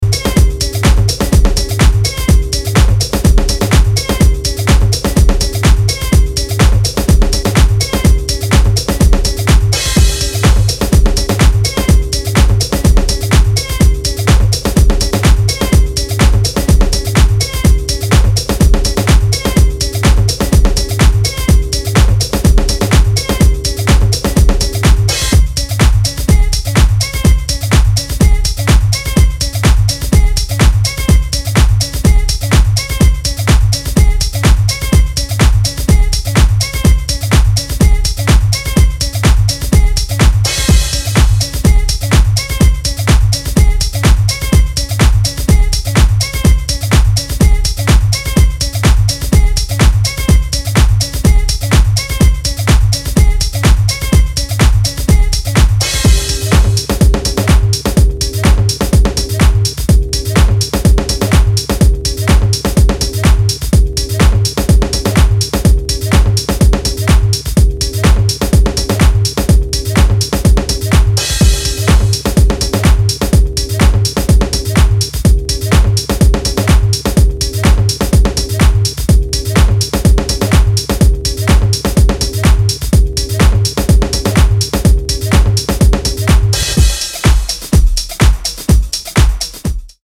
ミニマリーにグルーヴするファンキー・ハウス